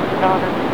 This is a recording that I got while at a local cemetery here in Macon, Missouri.
Its odd, but I believe that I hear a woman saying the word, "Daughter".